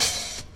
OpenHattingz.wav